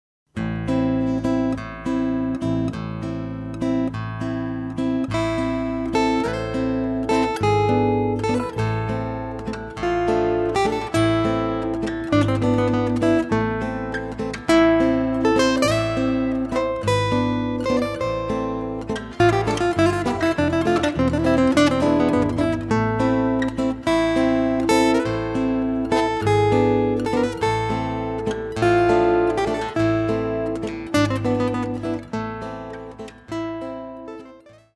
Chitarre e Basso